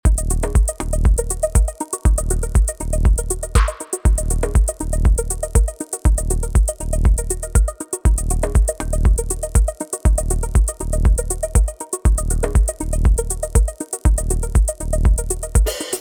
シンセの音色を変化させる
アルペジオで作られたこのシンプルなメロディーラインには、フェイザー, パン, ディレイのオートメーションがかけられています。